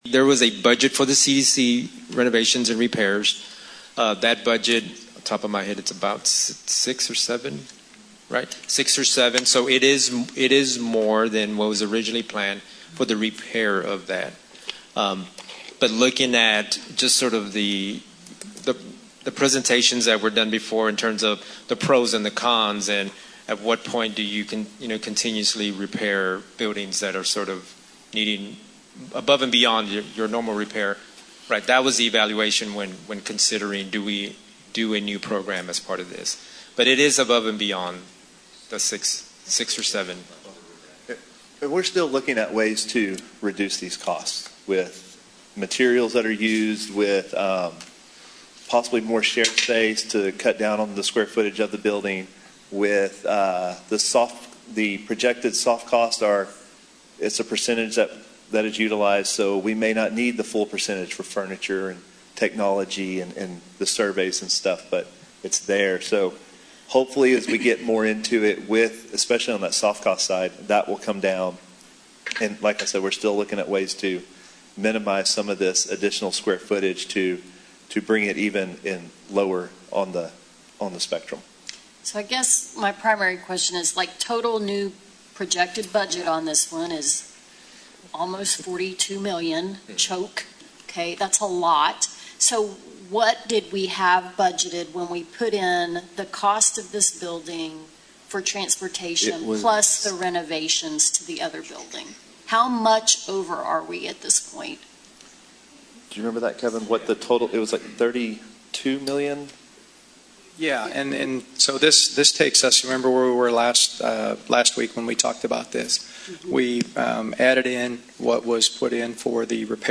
Click below for comments from the January 17, 2022 BISD school board meeting.